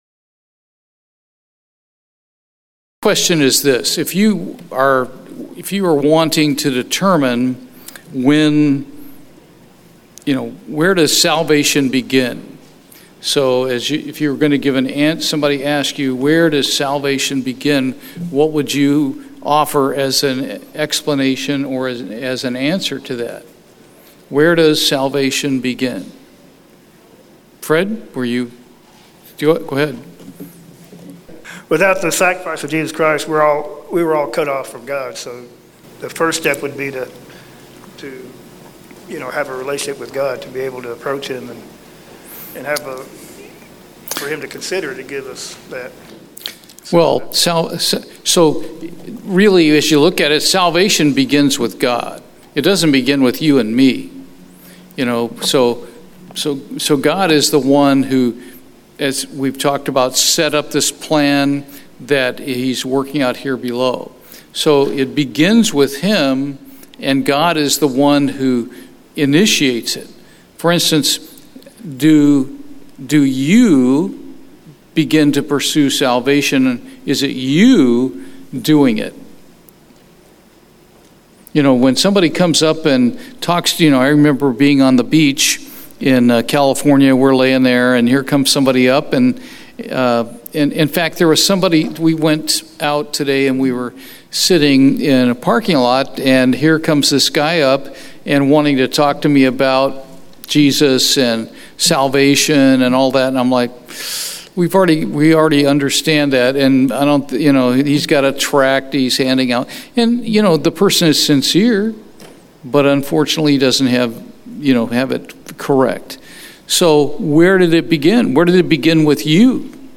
Bible Study, Questions About Salvation